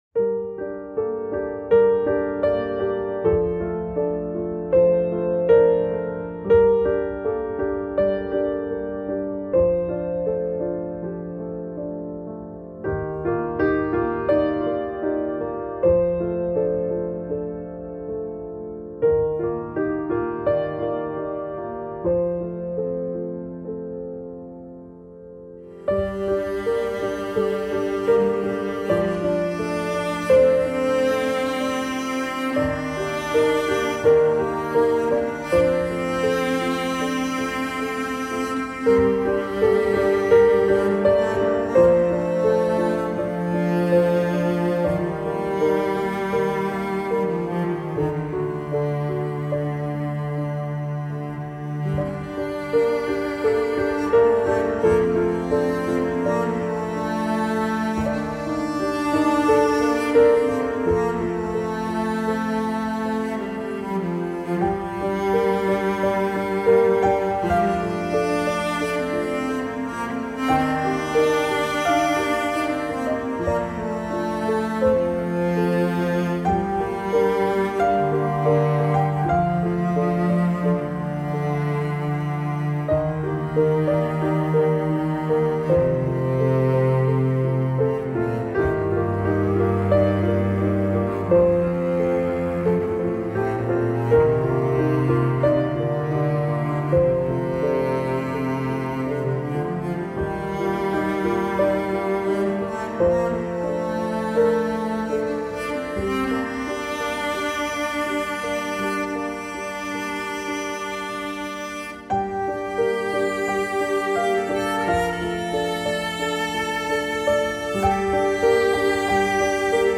پیانو
موسیقی بی کلام ابری و بارانی پیانو عصر جدید غم‌انگیز